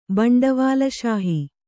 ♪ baṇḍavāl śahi